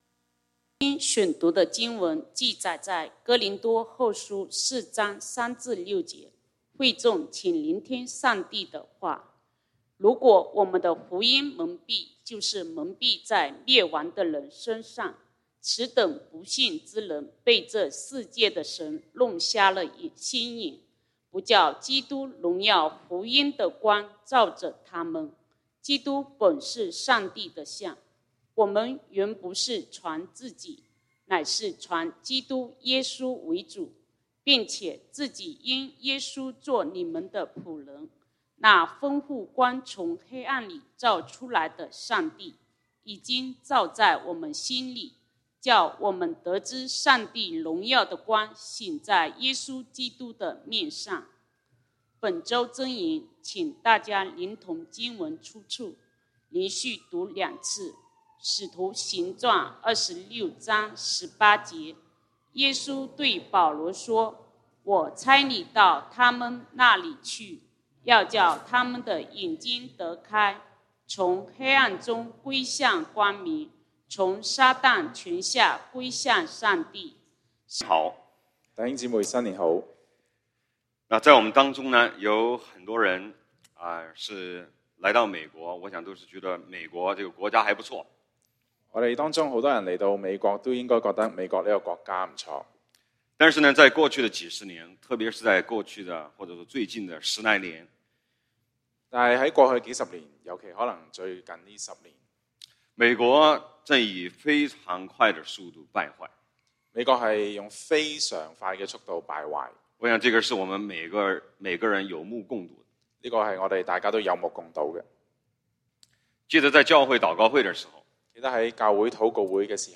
講道經文：《哥林多後書》2 Corinthians 4:3-6